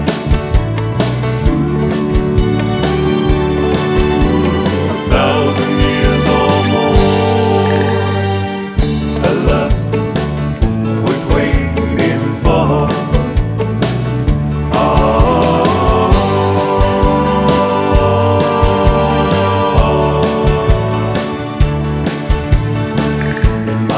One Semitone Down Pop (1980s) 3:18 Buy £1.50